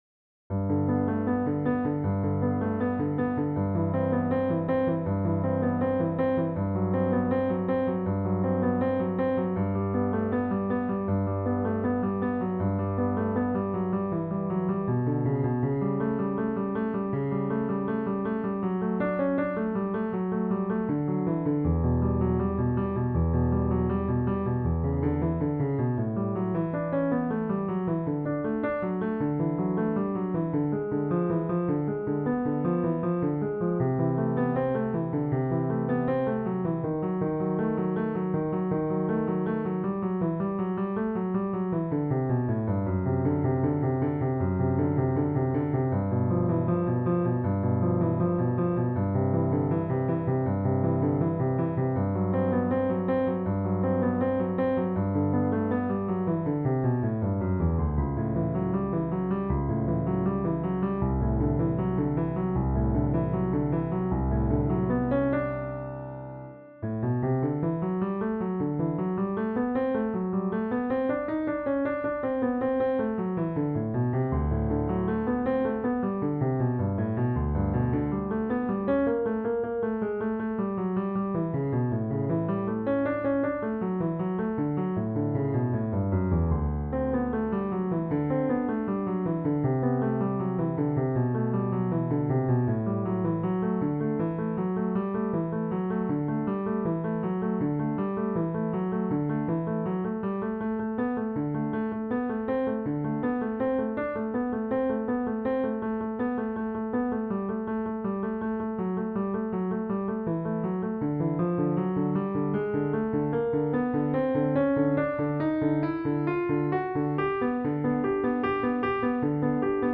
Piano Arrangements